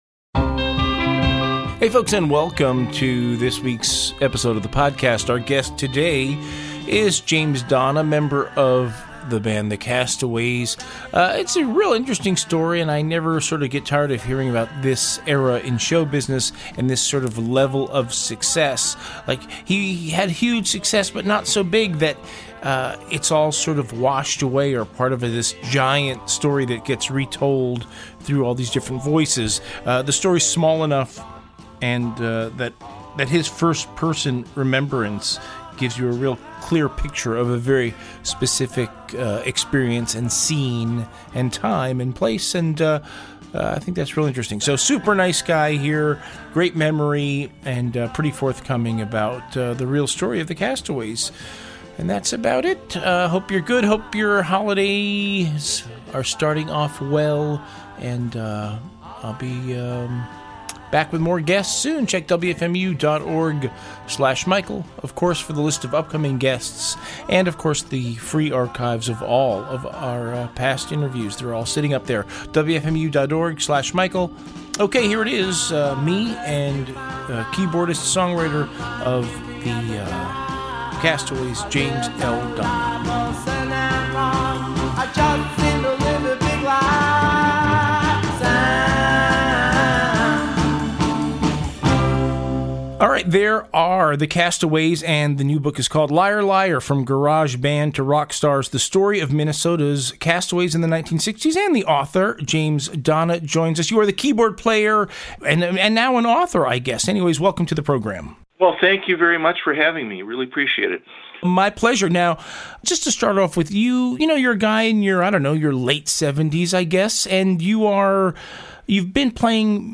"Interview"